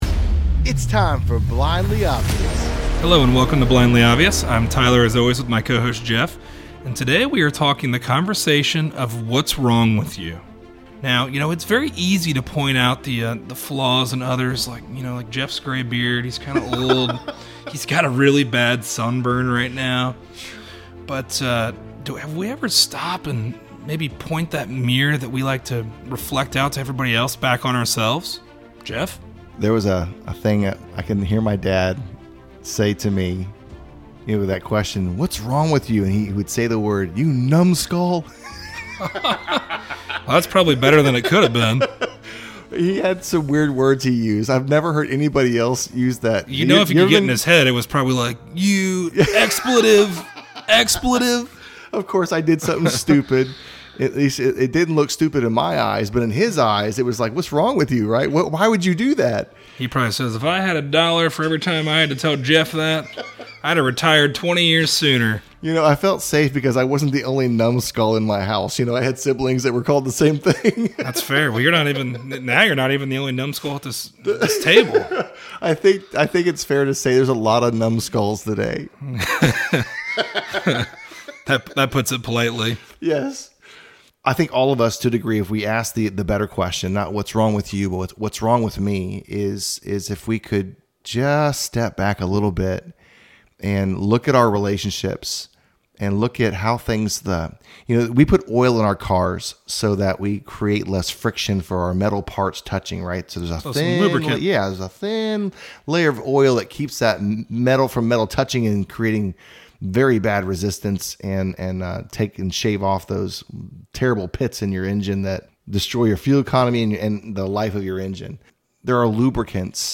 A conversation on what it is that’s wrong with us.